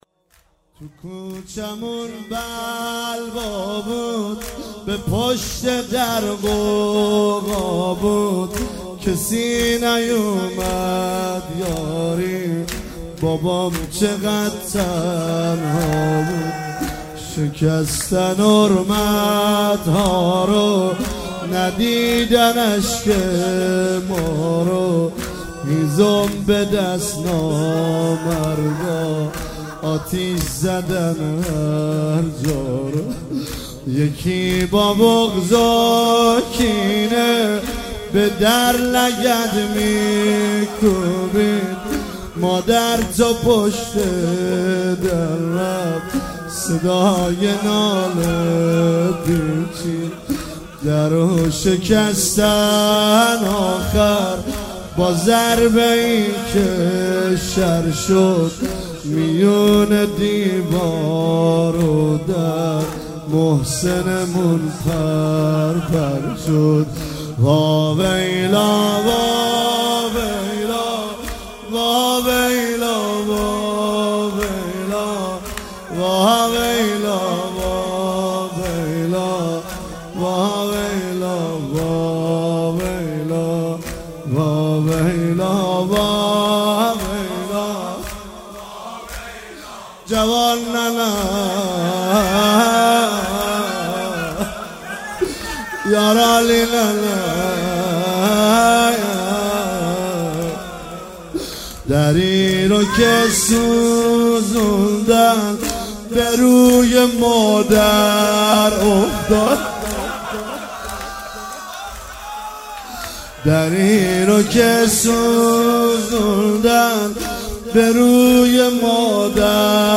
هفتگی 11 بهمن 96 - زمینه - تو کوچمون بلوا بود